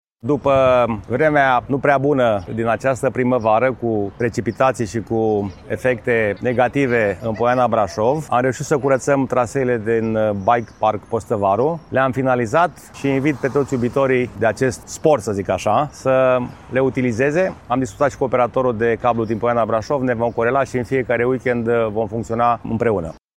Primarul municipiului Brașov, George Scripcaru.